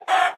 latest / assets / minecraft / sounds / mob / chicken / hurt2.ogg